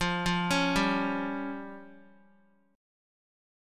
Fsus2#5 Chord